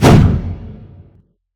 Punch Swing_HL_8.wav